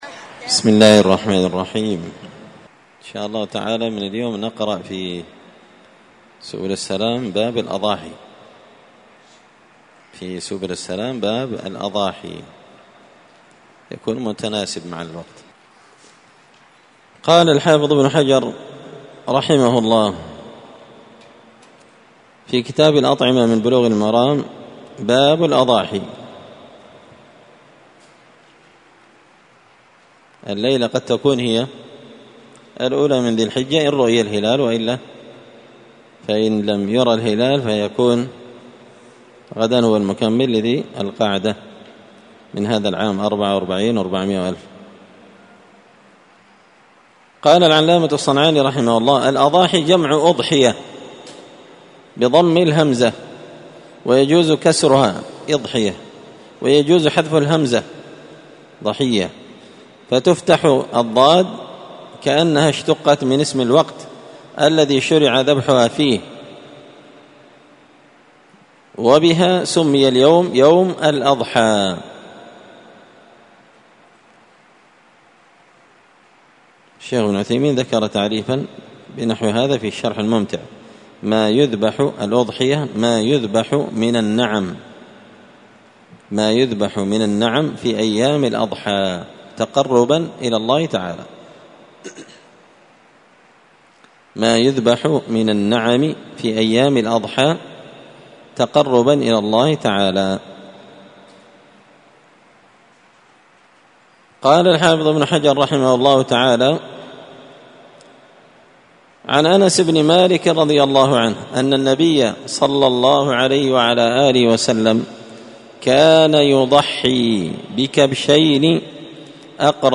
مسجد الفرقان_قشن_المهرة_اليمن